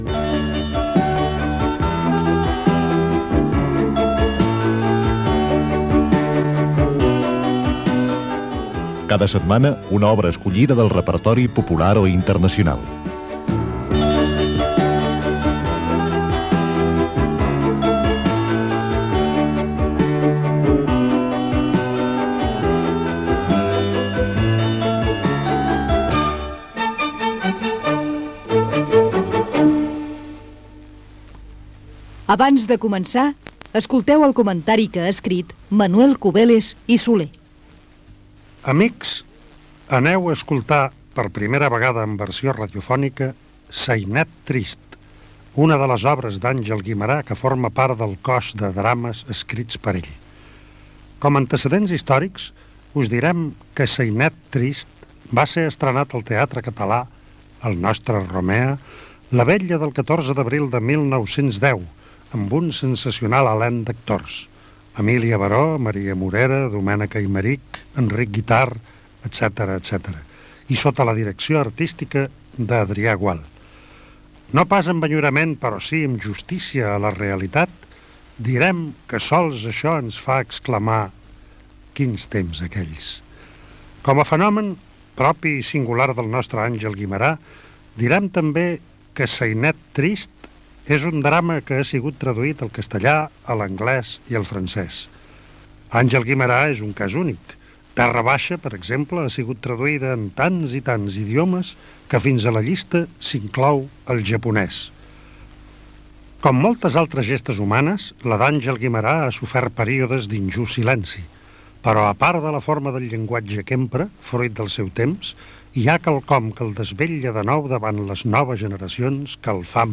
Versió radiofònica de "Sainet trist"(1910), d'Àngel Guimerà.